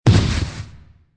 traphit_1.ogg